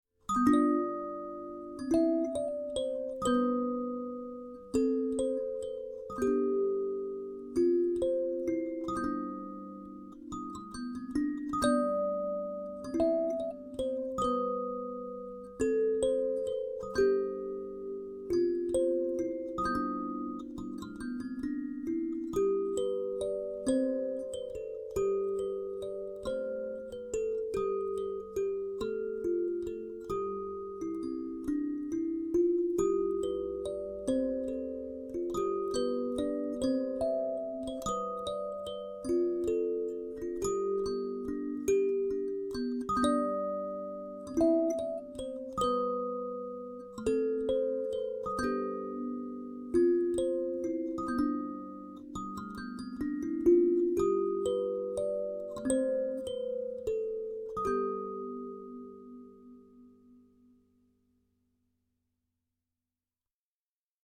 G Mixolydian